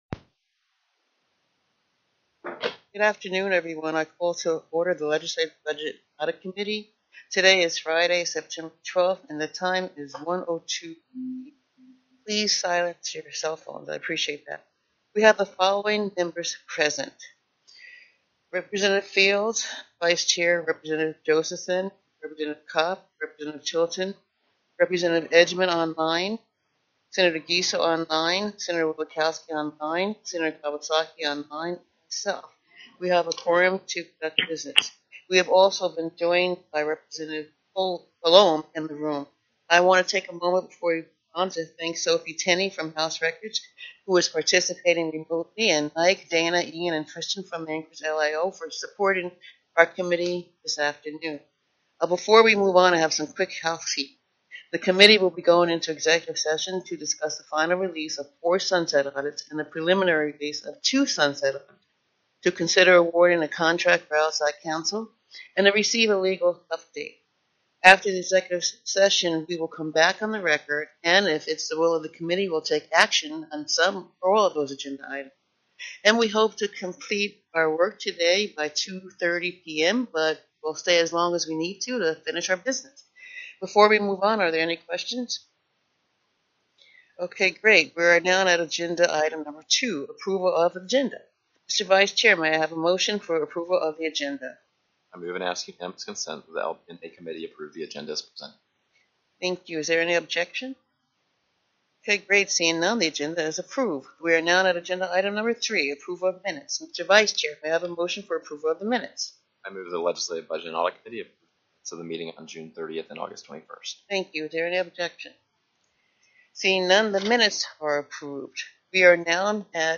09/12/2025 01:00 PM House LEGISLATIVE BUDGET & AUDIT
+ teleconferenced
The committee  took an at-ease  from 1:05  p.m. to 2:04  p.m. for